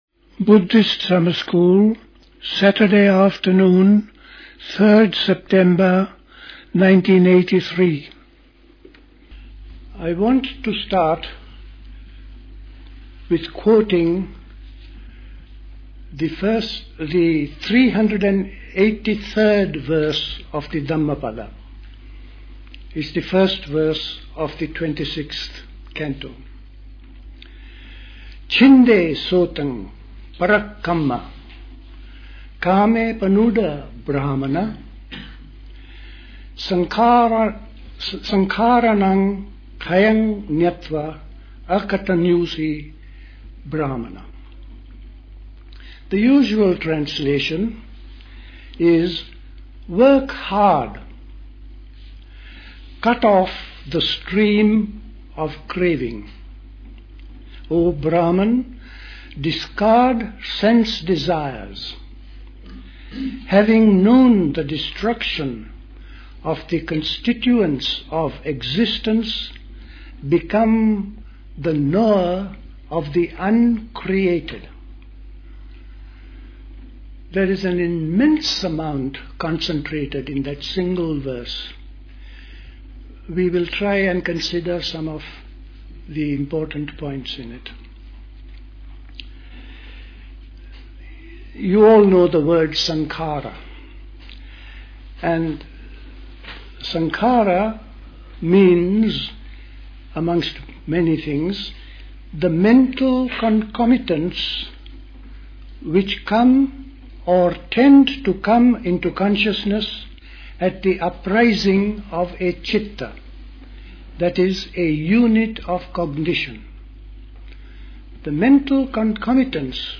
A talk
at High Leigh Conference Centre, Hoddesdon, Hertfordshire
The Buddhist Society Summer School